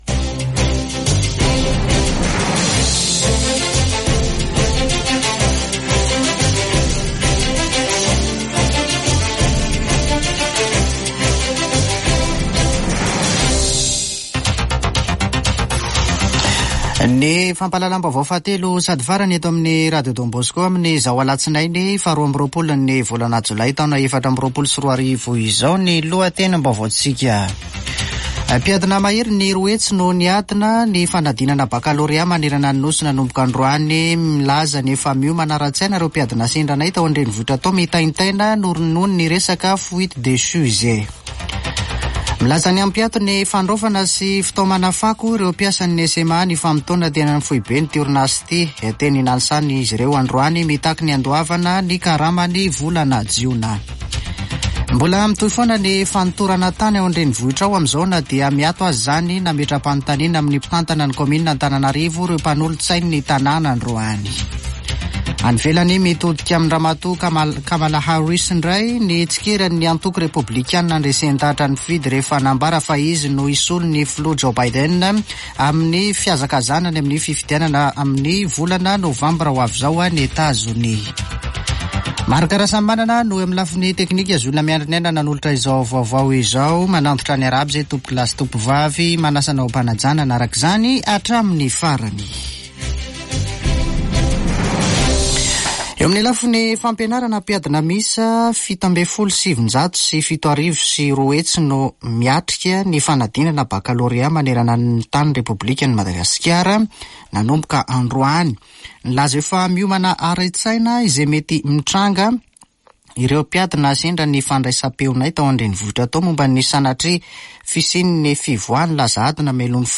[Vaovao hariva] Alatsinainy 22 jolay 2024